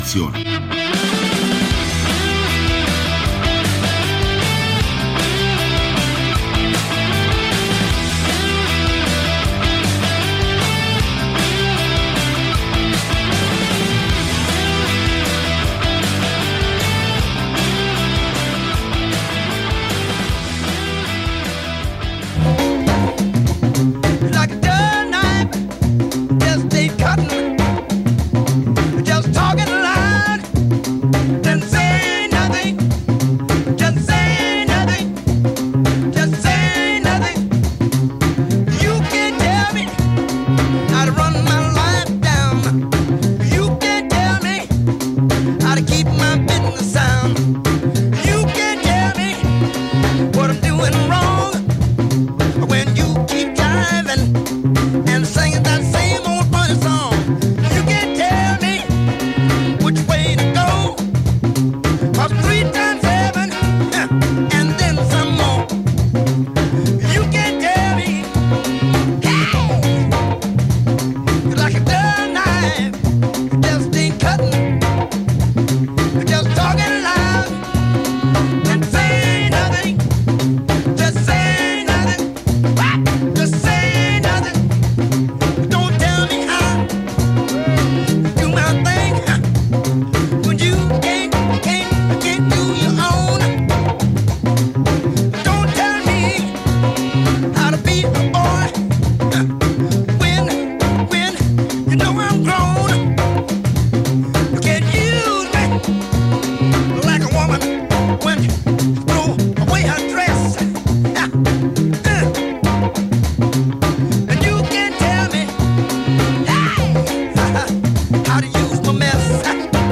Ma non sono mancati, come sempre, la musica e qualche notizia!